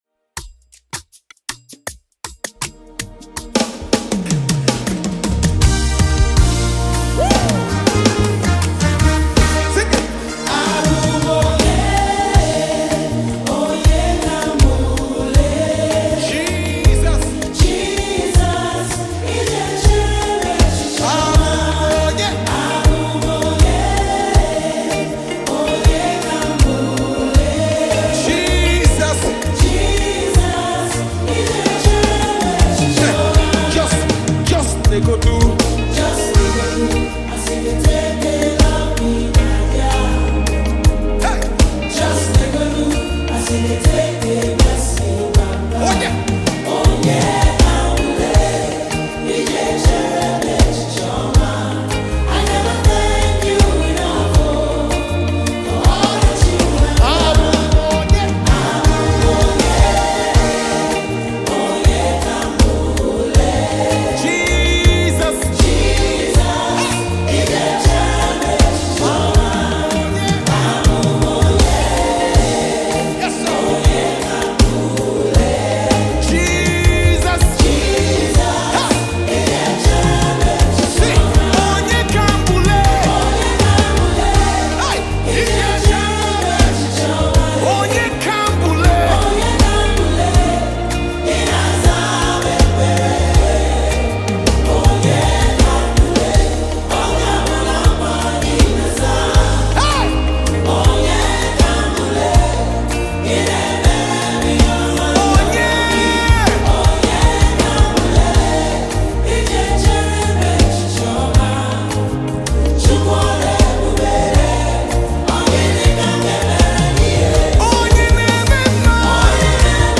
September 11, 2024 admin Gospel, Music 0